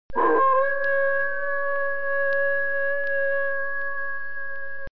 (a fairy / to dance) I . 5. cri_loup-garou.mp3 (a werewolf / to howl) . 6.
cri_loup-garou.mp3